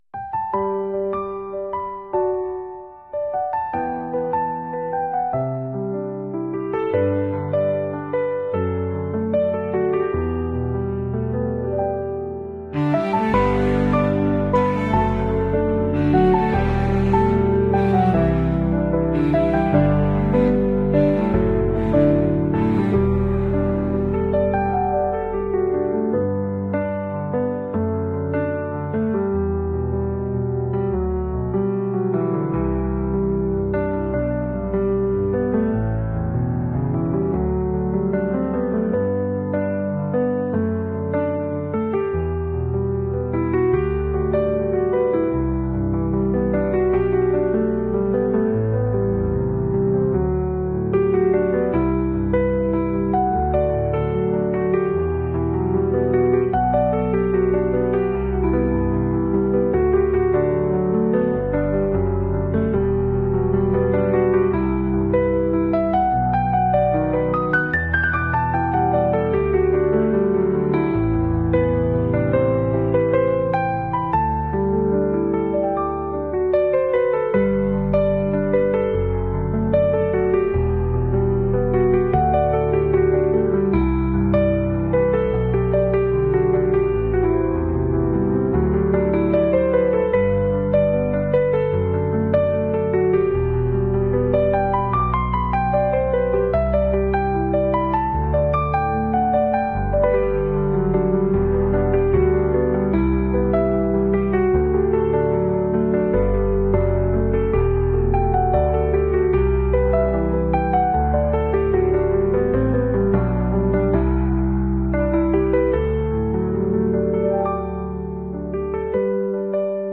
🎧 Day 2: Reading.